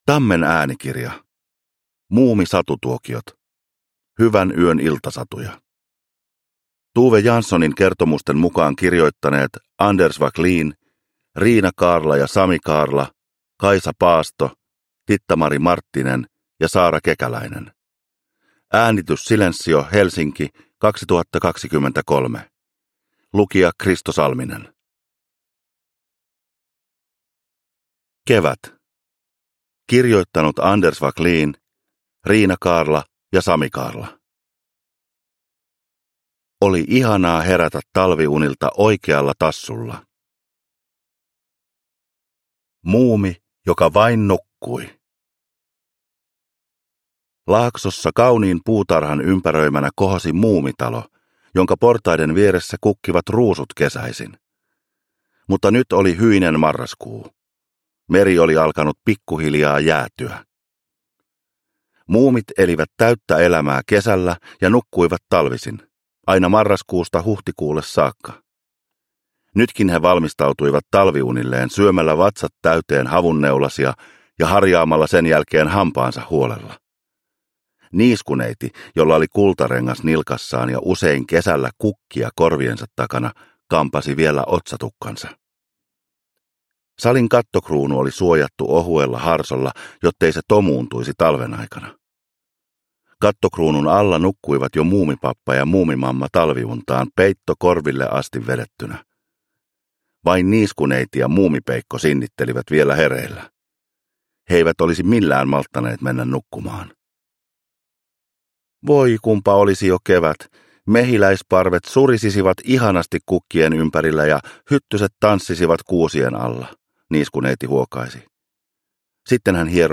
Uppläsare: Kristo Salminen